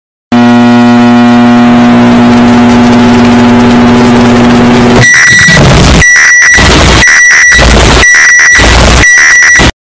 BEEP BEEP BEEP Download
Black-Microwave-Earrape.mp3